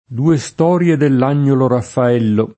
due St0rLe dell #n’n’olo raffa$llo] (Vasari)